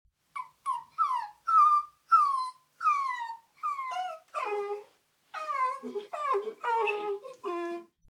Download Puppy sound effect for free.
Puppy